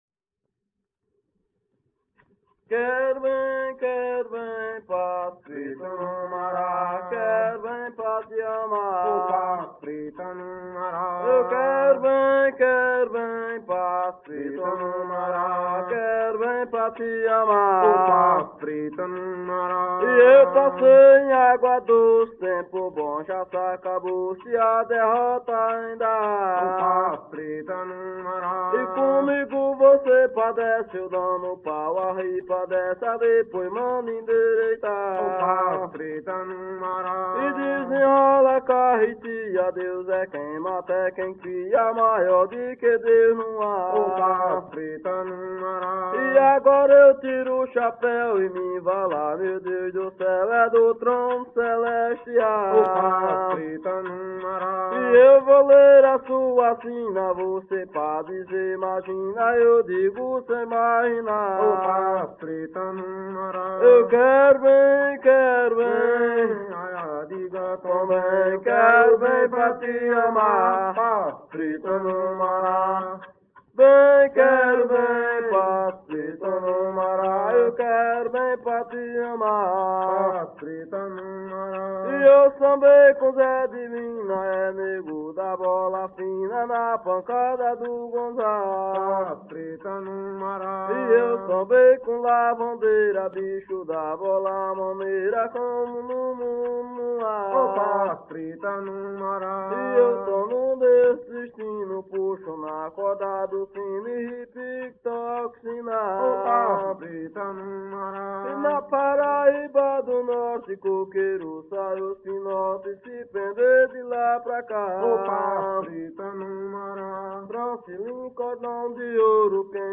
Coco embolada -""O pass'o preto no mará""